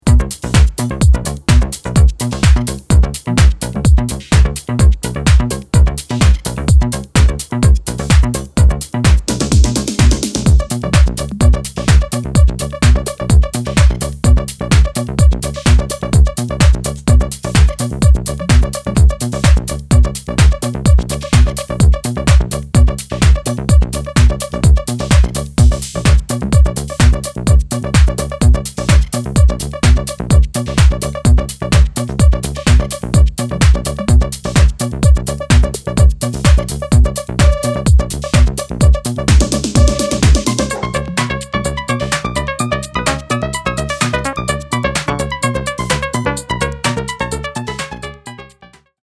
全体的にかなりソリッドにまとめられ